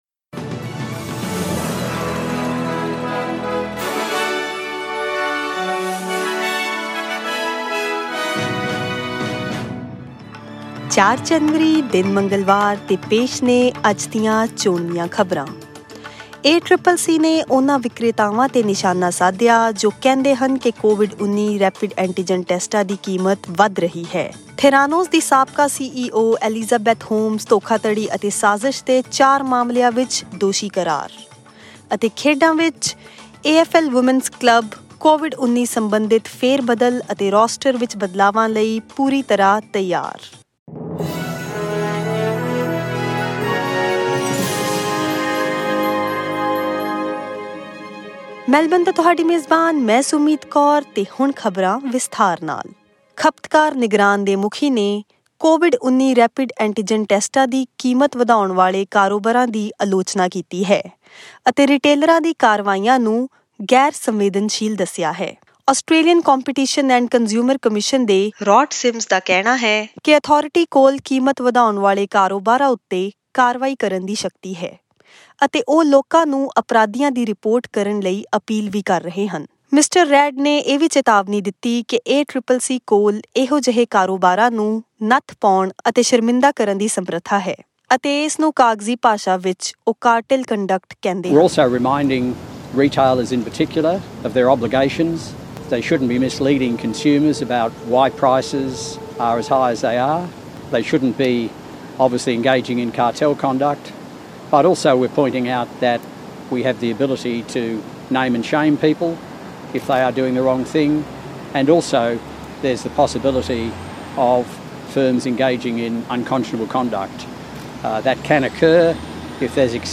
Listen to the latest news headlines in Australia from SBS Punjabi radio.
Click on the player at the top of the page to listen to the news bulletin in Punjabi.